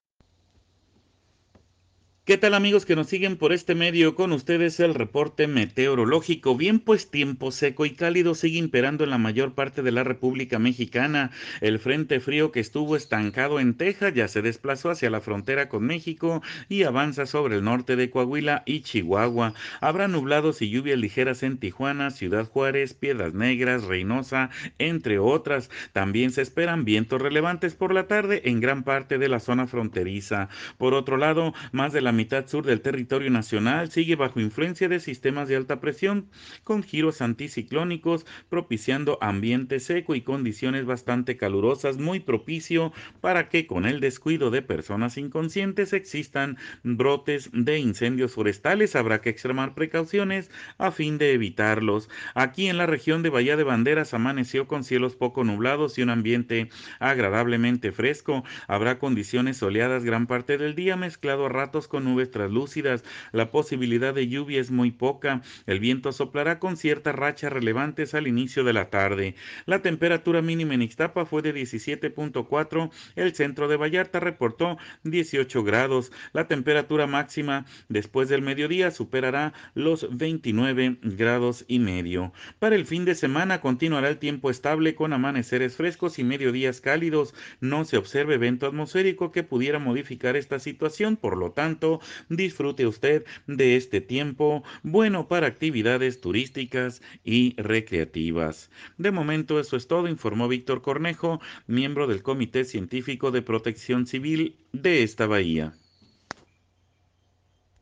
PRONÓSTICO DEL TIEMPO PARA LA REGIÓN DEL PACÍFICO CENTRO